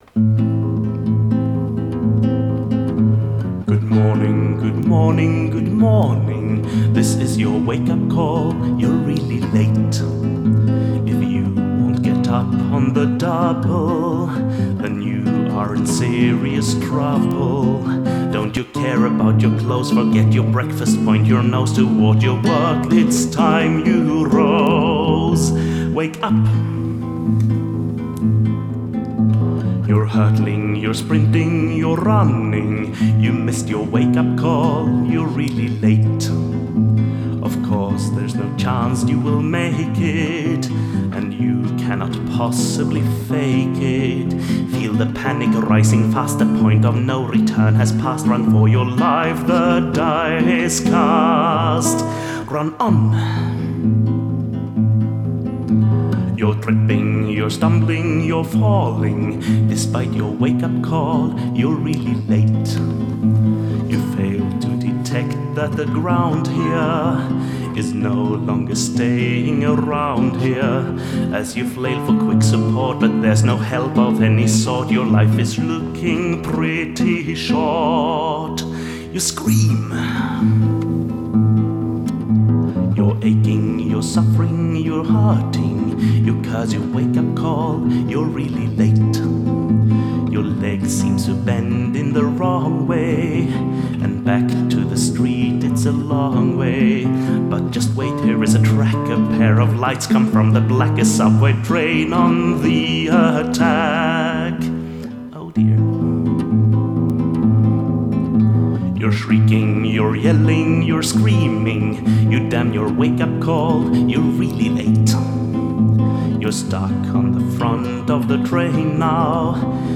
Style: Song
A black humor song